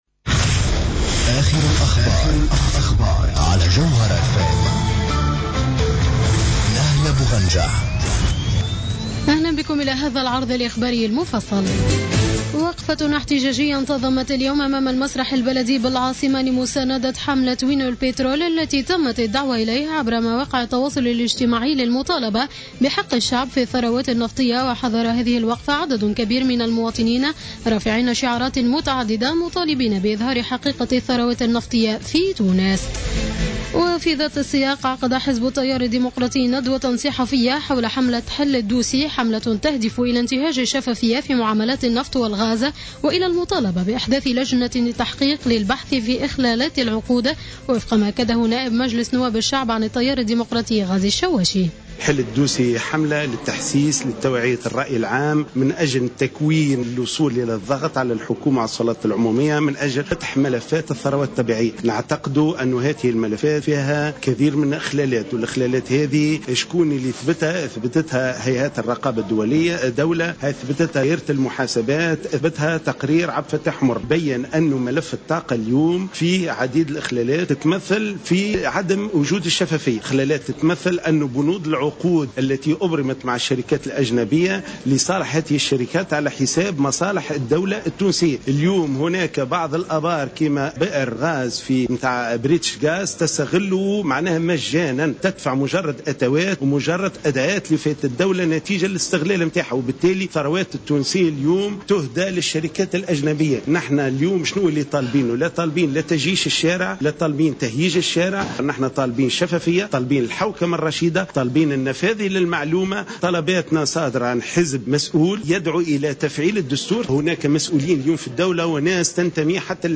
نشرة أخبار السابعة مساء ليوم السبت 30 ماي 2015